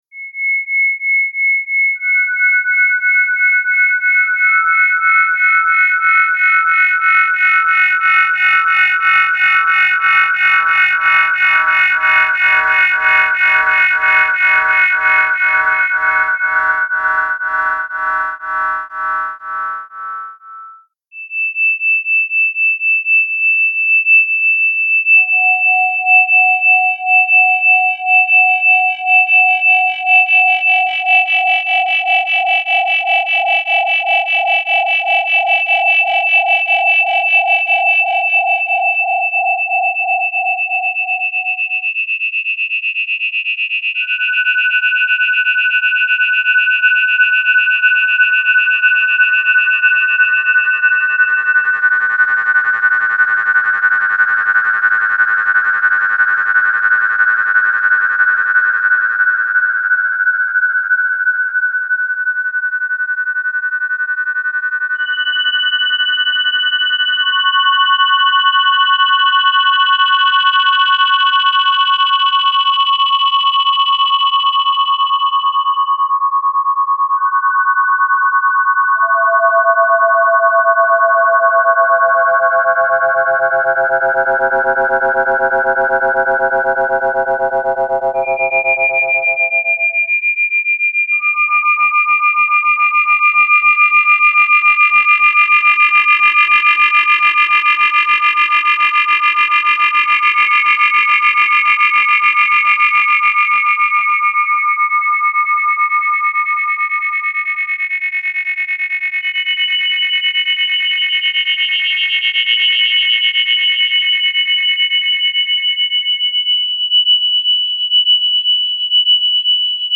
Just Intonation
In this piece, I have detuned the left and the right channels. As the piece starts, the detuning falls randomly in between 2 Hz and 20Hz. As it progresses, the range narrows until the left and the right always differ by 10Hz.
Dans cette pièce la droite et la gauche ne sont pas en accord.